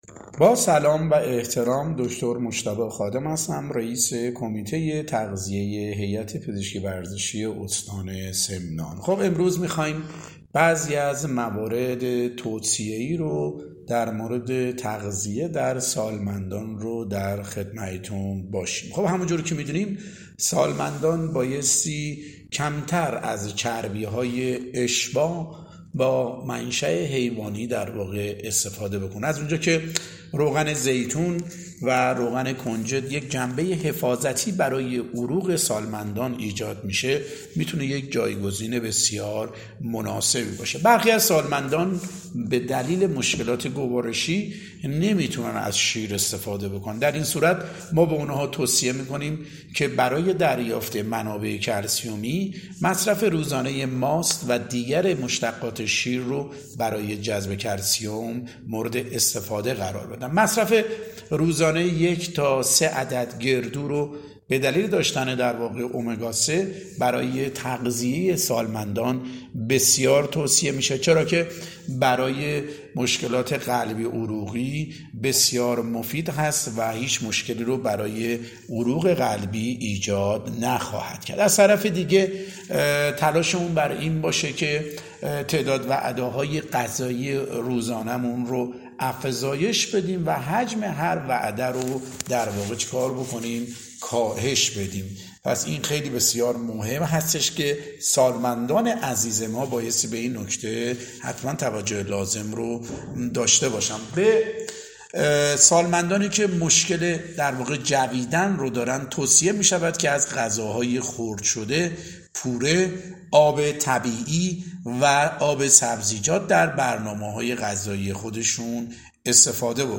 صوت آموزشی/روز جهانی سالمند